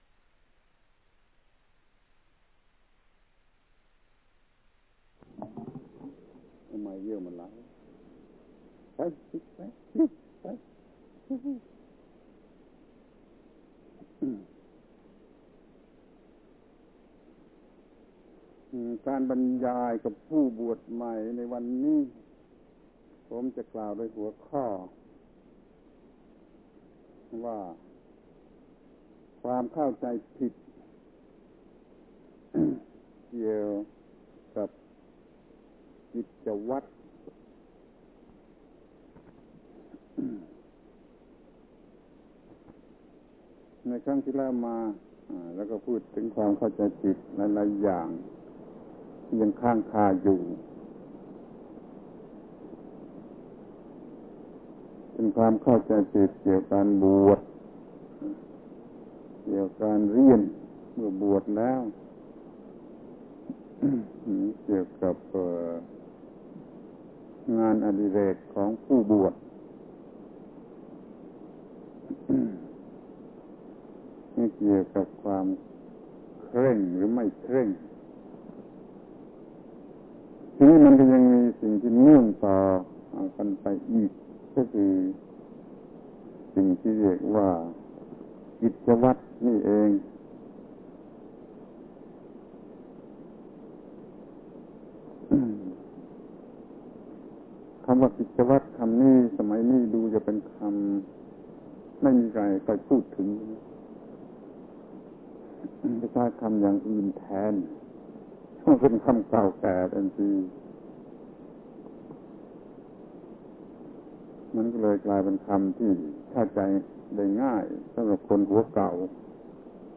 การบรรยายที่เป็นการแนะนำสั่งสอนอบรมผู้บวชใหม่ในครั้งที่ ๖ นี้ ผมจะพูดโดยหัวข้อว่าความเข้าใจถูกเกี่ยวกับพระรัตนตรัย คือ เรื่องพระรัตนตรัยที่ผู้บวชใหม่ควรจะเข้าใ ...